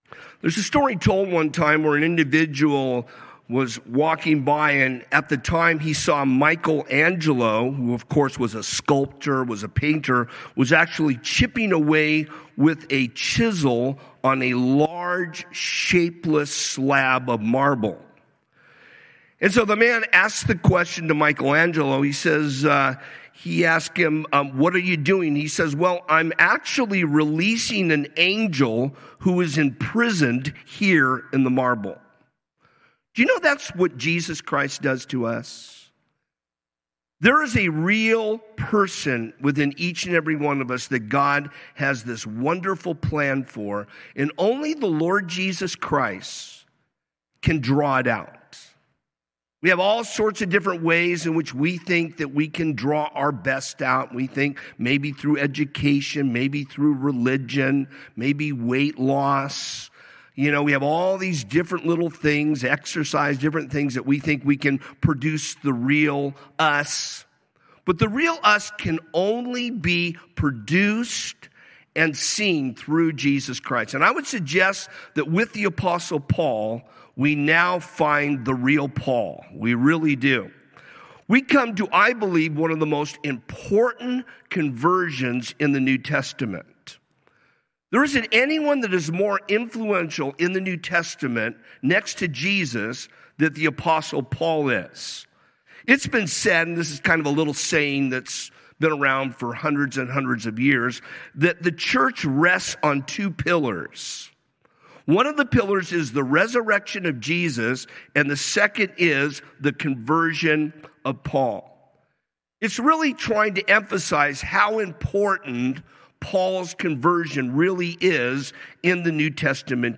Calvary Chapel Rialto – Sermons and Notes